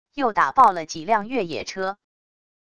又打爆了几辆越野车wav音频生成系统WAV Audio Player